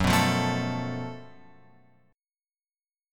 F# Minor Major 7th Double Flat 5th